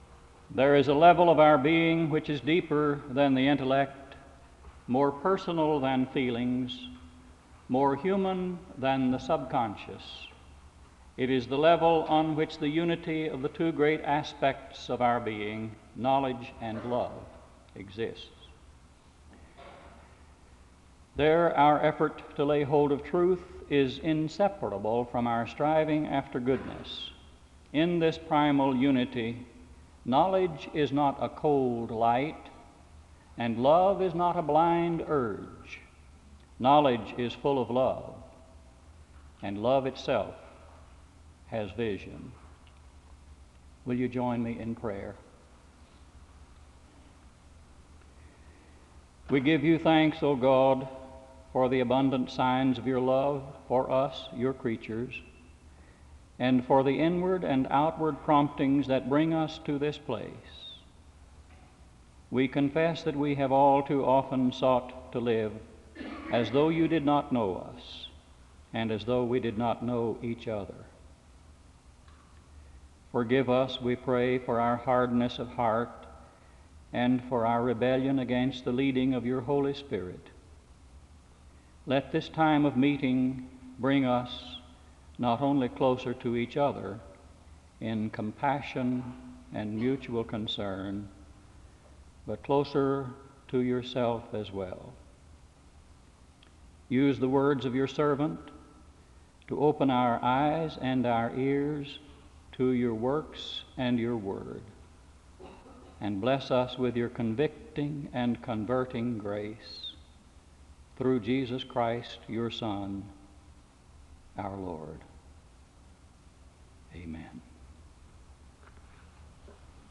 The service opens with a word of prayer (00:00-02:00).
The choir leads in a song of worship (05:27-08:39).
The choir ends the service with a song of worship (30:39-31:52).